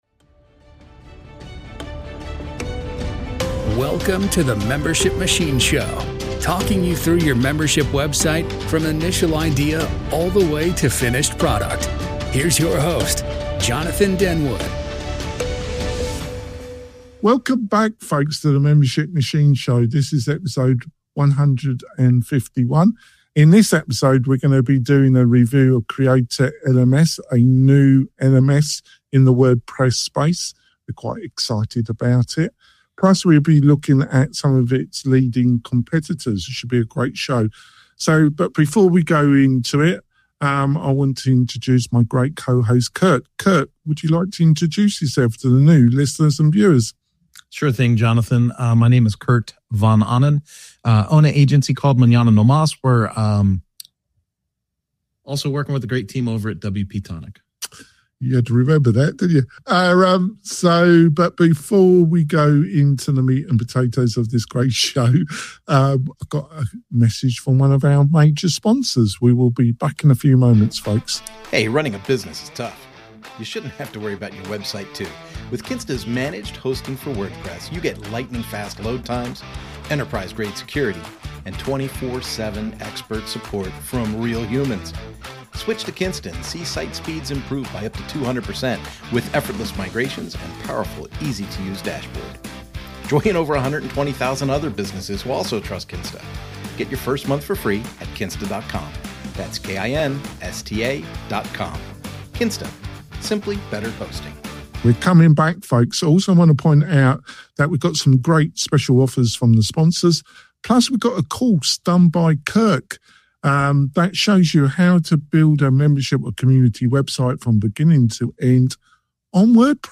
We interview creative WordPress and startup entrepreneurs, plus online experts who share insights to help you build your online business.